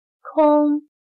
\kōng\Vacío; cielo; aire